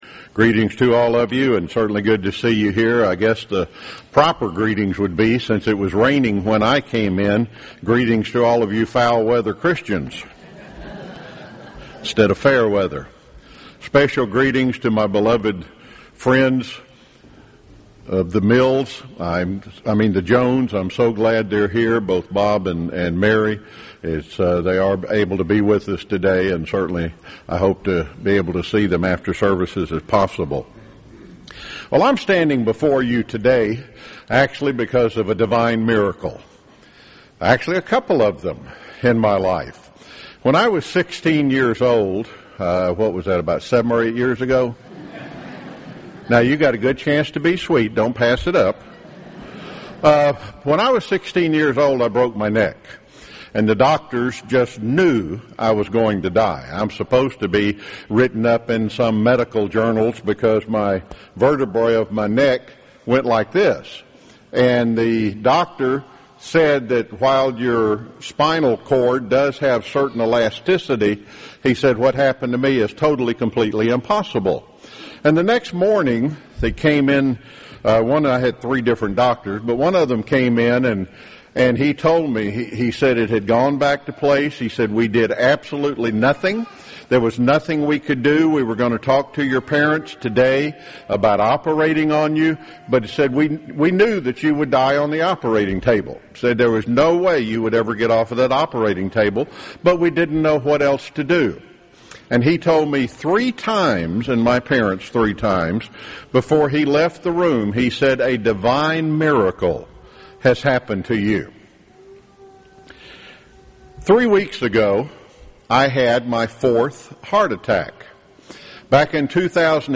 This sermon was given at the Jekyll Island, Georgia 2013 Feast site.